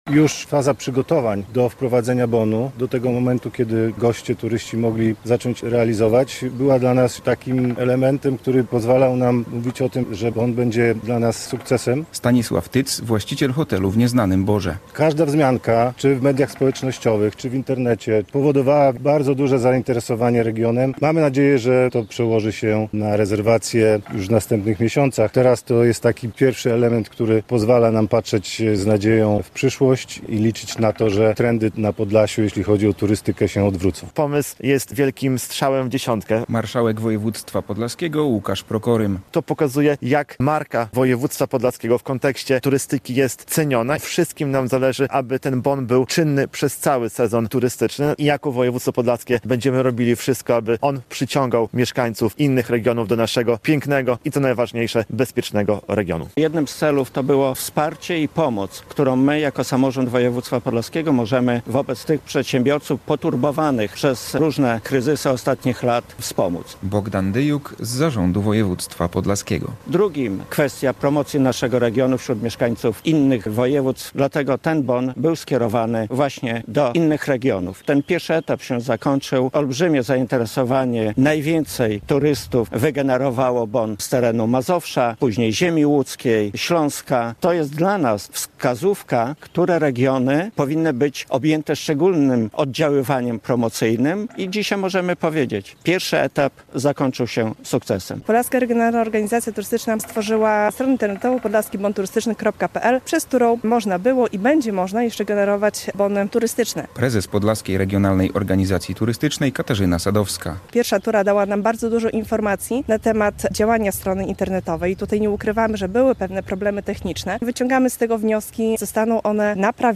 Konferencja - Podlaski Bon Turystyczny, 24.04.2025, fot.
Wszystkim nam zależy, aby ten bon był czynny przez cały sezon turystyczny i jako województwo podlaskie będziemy robić wszystko, aby przyciągał mieszkańców innych regionów - mówi marszałek województwa podlaskiego Łukasz Prokorym.